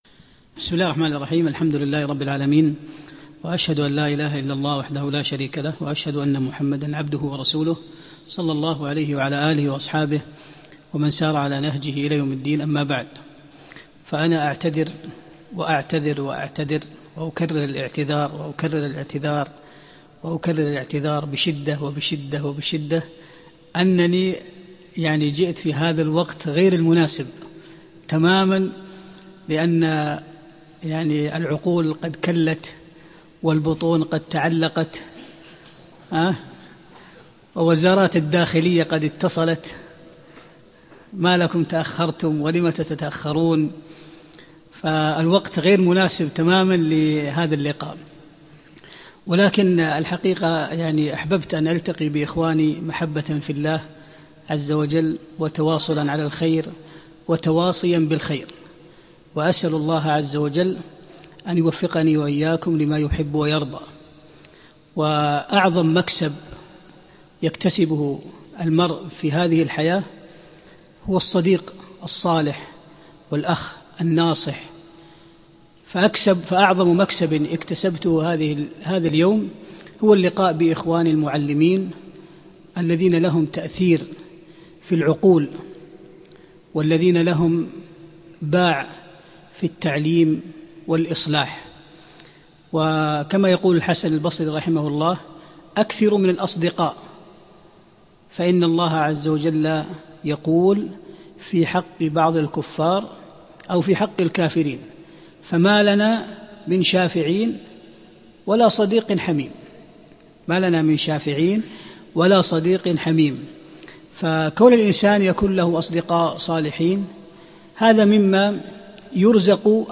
من محاضرات الشيخ في دولة قطر
لقاء توجيهي تربوي مع المعلمين في مدرسة حسان بن ثابت - قطر